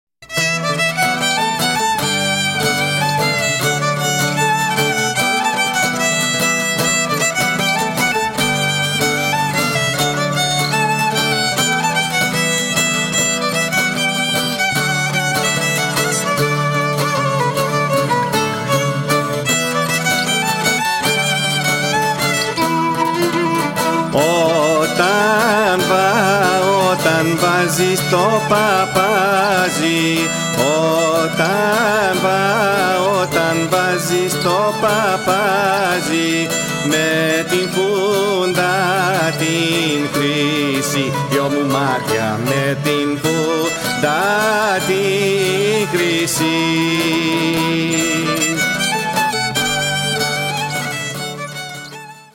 Syrtos from Skyros